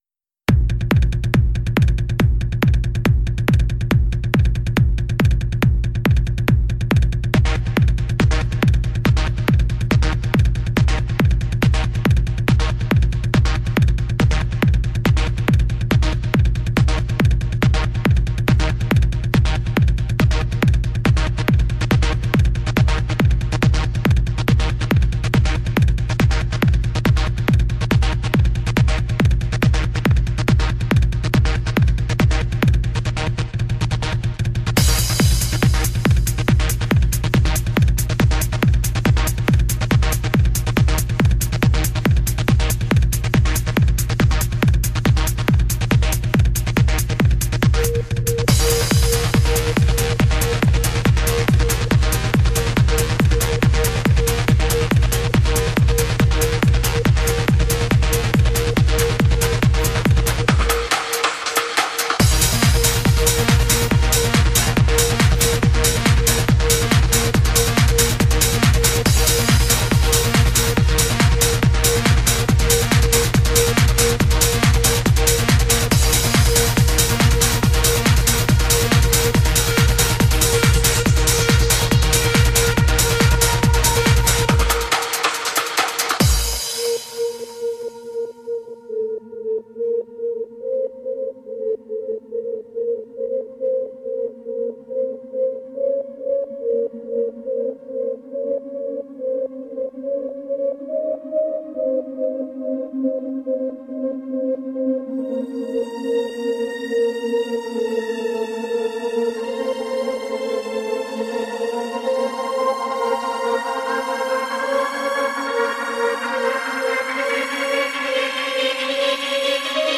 Adagio for strings - Logitrem audio